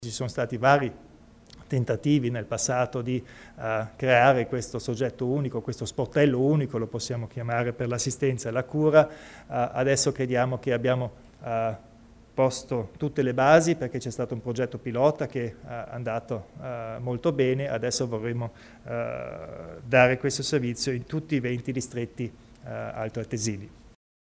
Il Presidente Kompatscher illustra le novità nel settore dell'assistenza alle persone